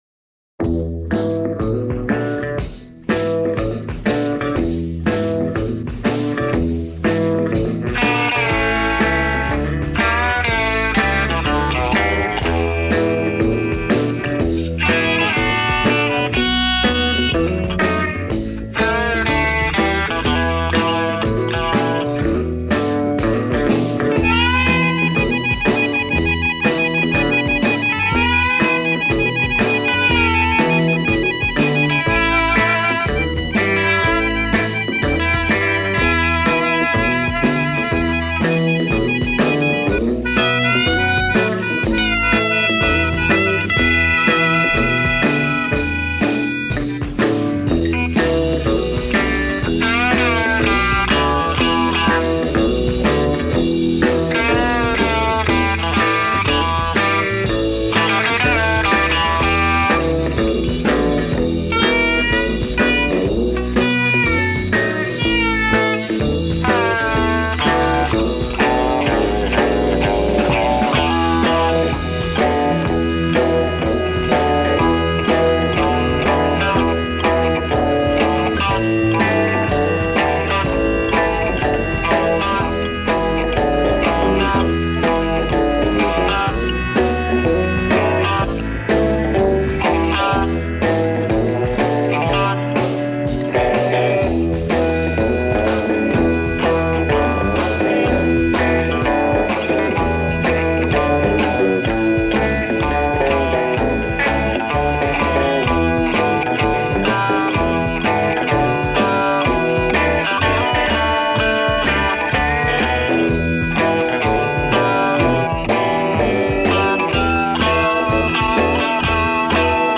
mixed and acetate cut 30 November 1965